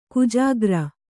♪ kujāgra